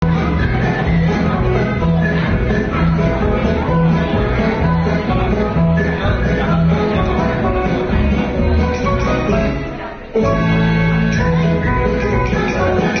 舞蹈《快乐的小羊》